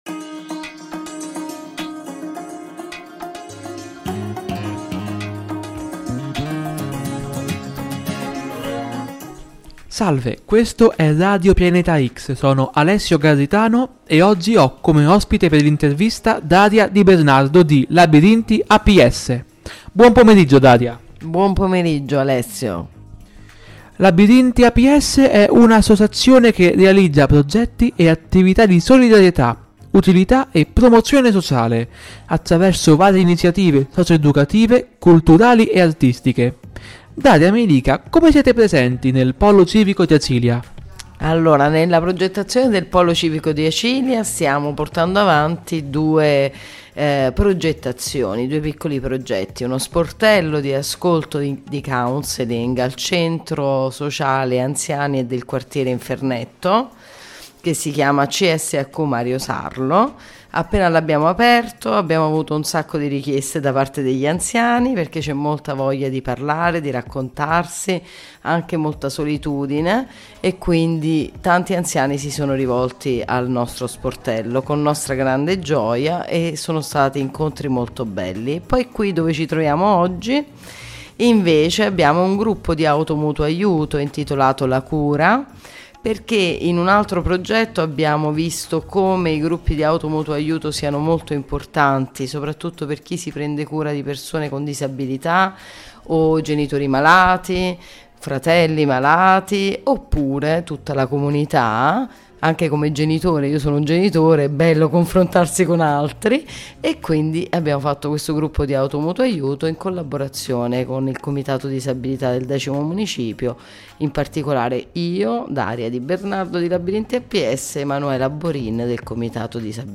INTERVISTA-LABIRINTI.mp3